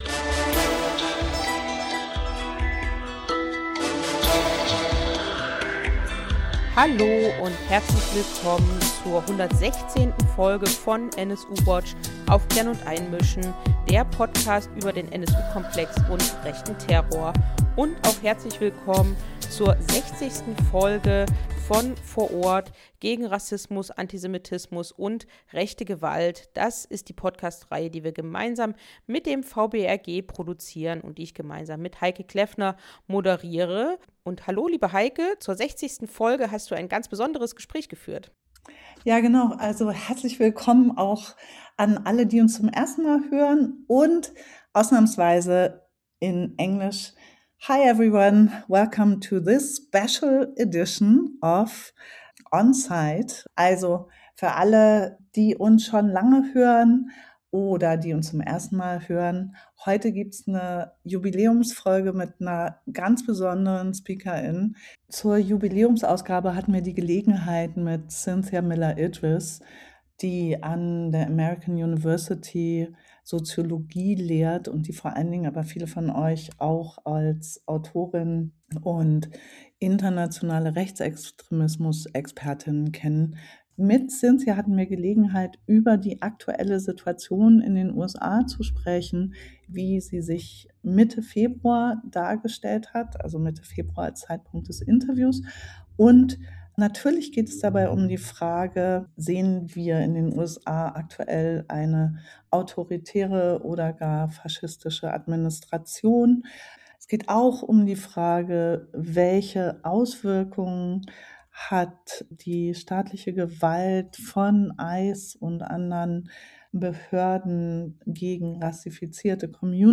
Staatsgewalt, Rassismus und Misogynie in den USA. Ein Gespräch mit Cynthia Miller-Idriss.